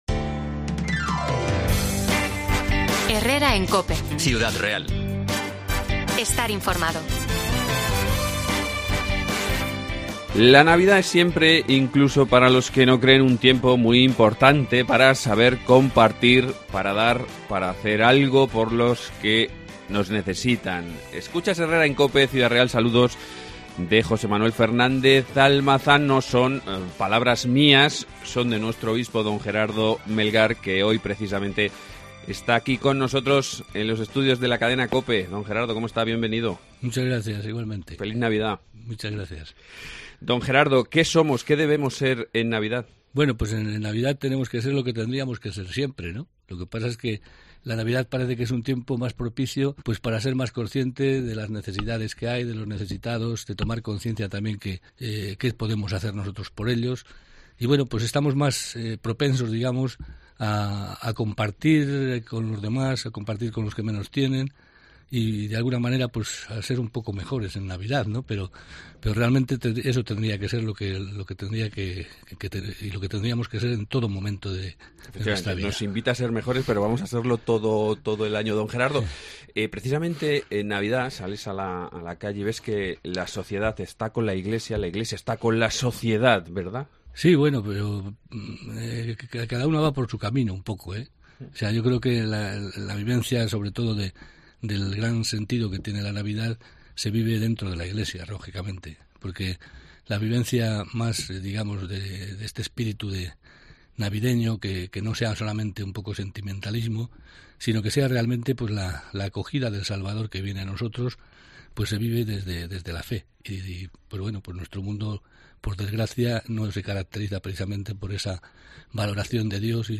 Entrevista al Obispo de Ciudad Real, Mons. Gerardo Melgar - 12,50 horas